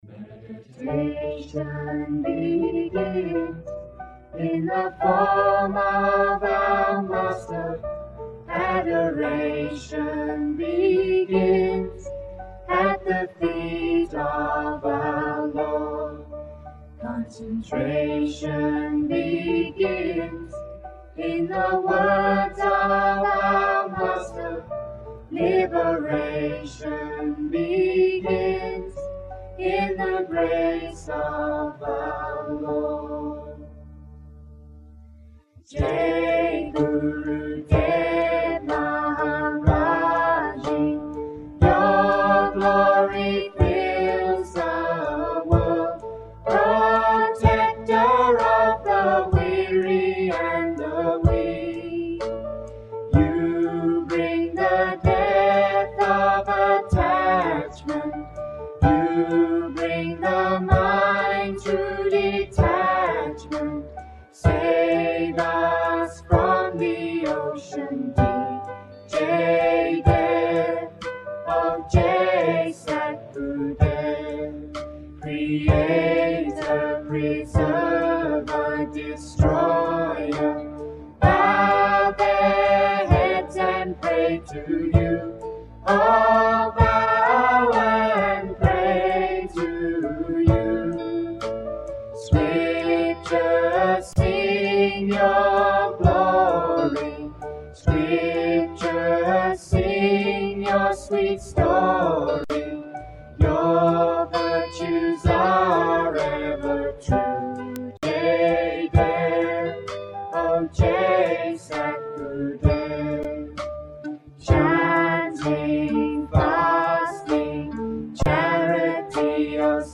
Arti is a Hindu ceremony of worship which was sung to Prem Rawat daily for the first decade of his career in the West.
The entire ceremony normally lasted around 30 minutes depending upon the speed of the singing.